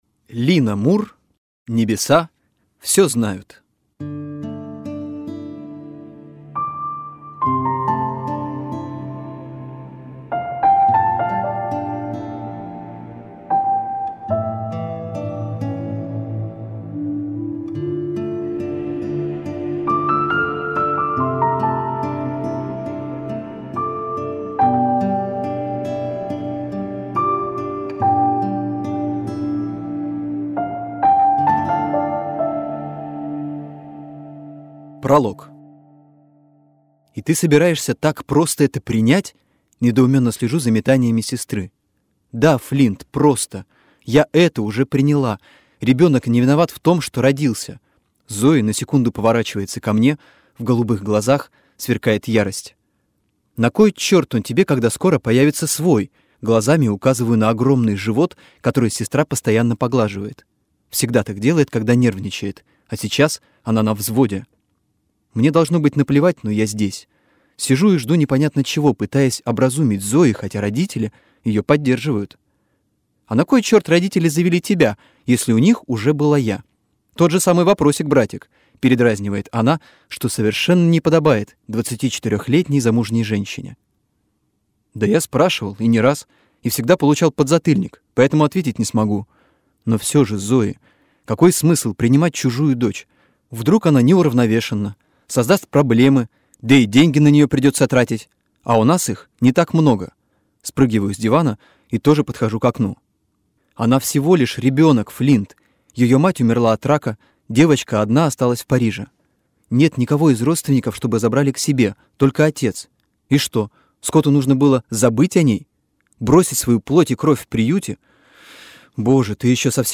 Аудиокнига Небеса всё знают | Библиотека аудиокниг